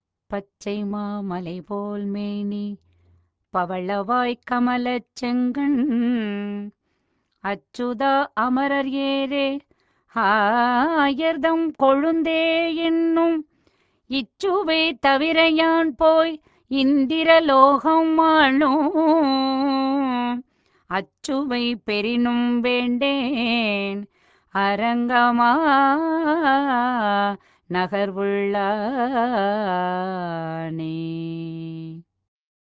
என்று தொண்டரடிப்பொடி ஆழ்வாரும் பாடுவதைக் கேட்கலாம்.